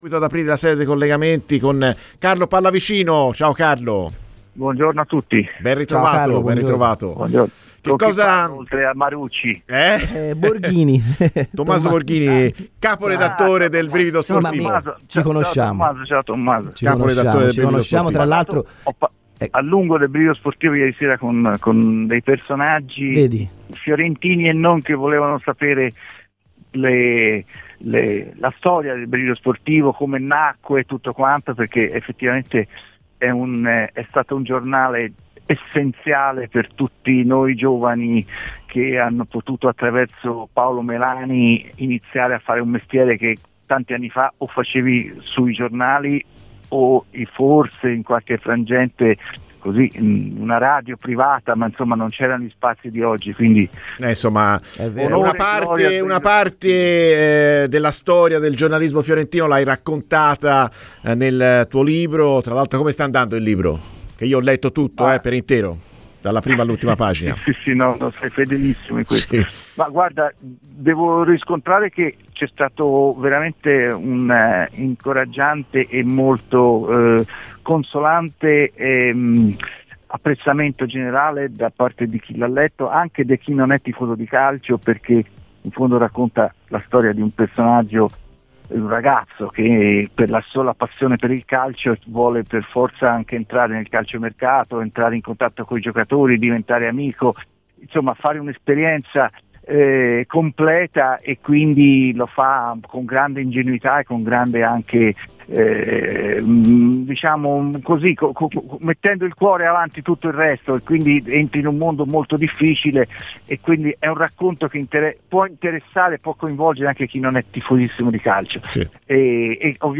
Ascolta il podcast per l'intevrista completa